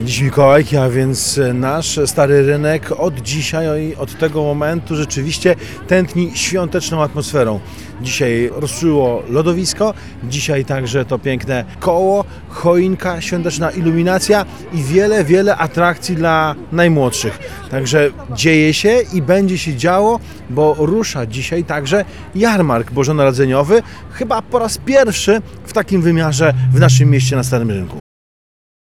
Przekazał nam Prezydent Miasta Płocka Andrzej Nowakowski.